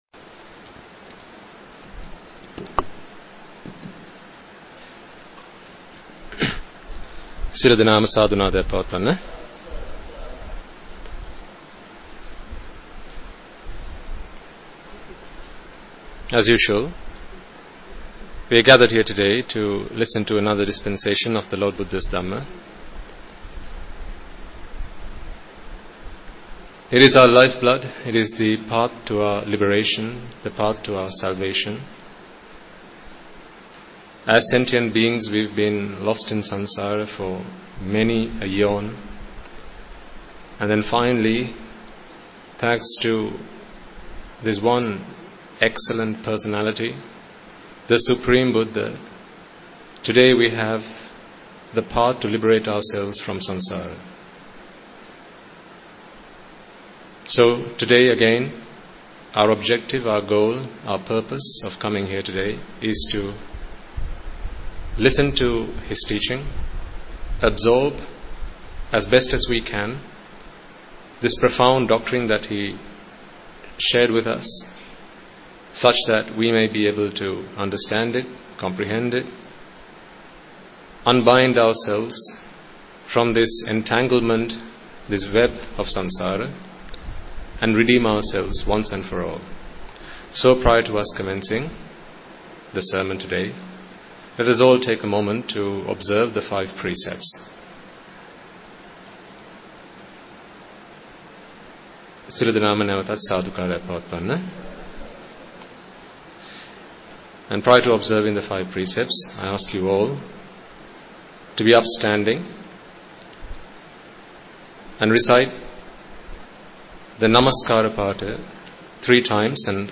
Sermon
English Dhamma Sermon on 2018-09-09